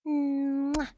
SFX亲吻2音效下载